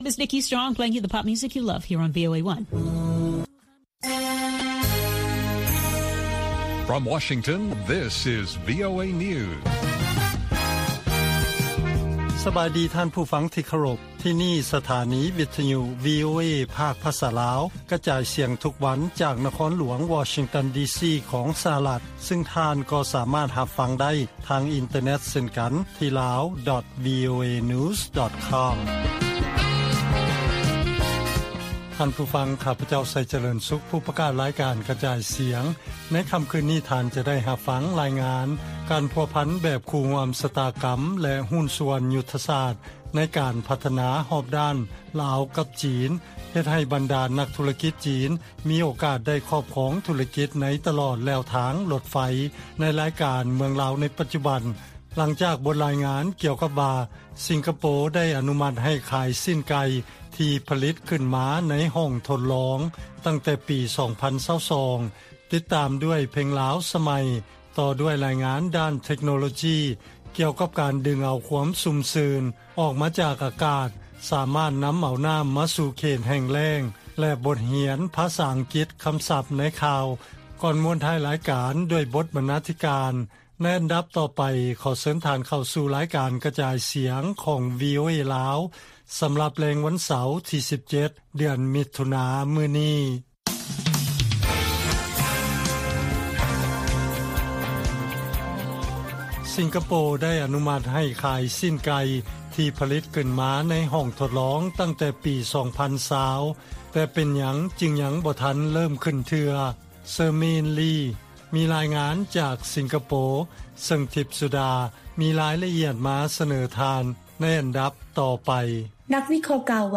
ລາຍການກະຈາຍສຽງຂອງວີໂອເອລາວ ວັນທີ 17 ມິຖຸນາ 2023